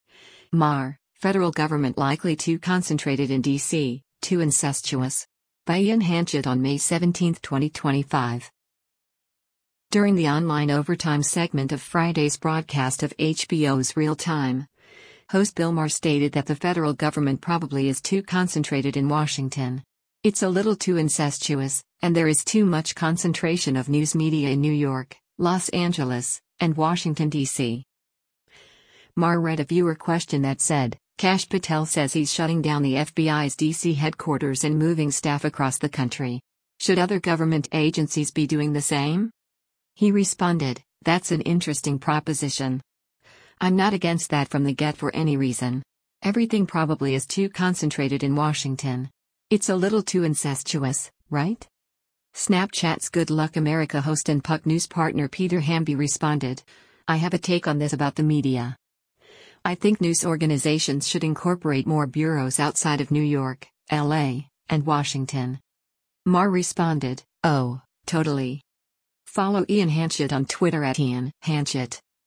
Maher read a viewer question that said, “Kash Patel says he’s shutting down the FBI’s D.C. headquarters and moving staff across the country. Should other government agencies be doing the same?”